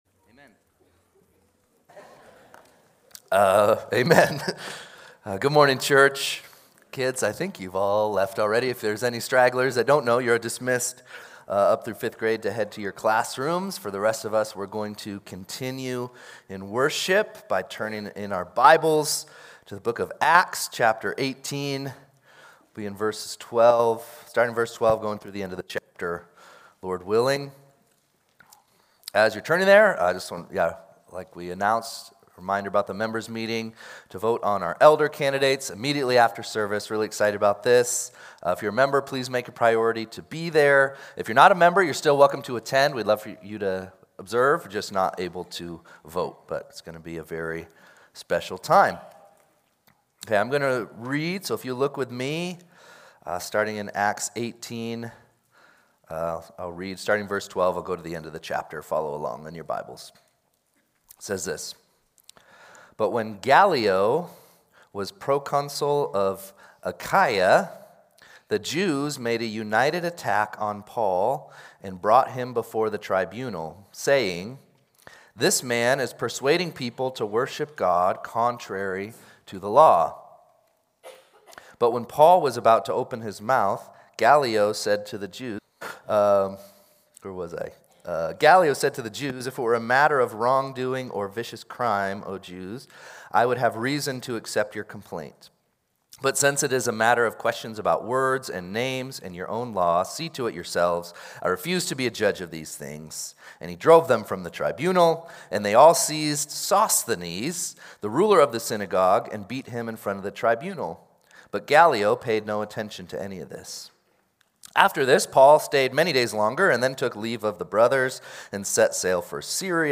2-15-26-Sunday-Service.mp3